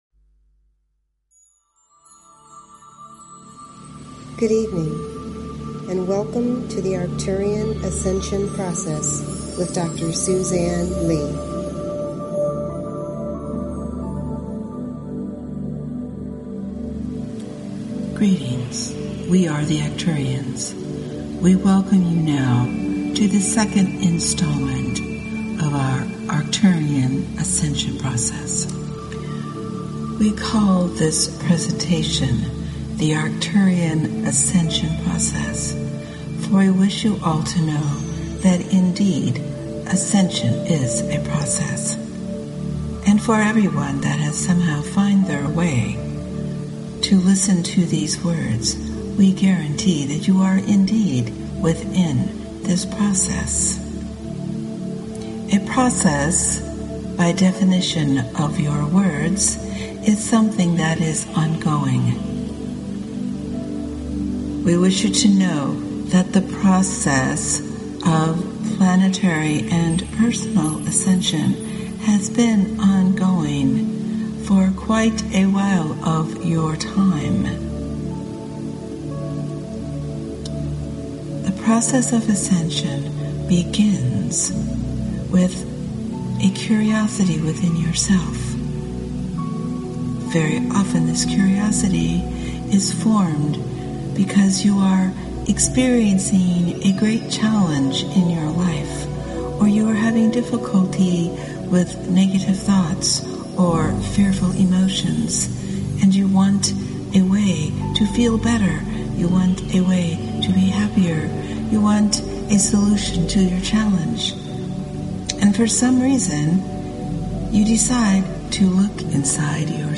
Talk Show Episode, Audio Podcast, Arcturian_Ascension_Process and Courtesy of BBS Radio on , show guests , about , categorized as
Arcturian guided meditation and Healing the Self, Healing the Mother Part 2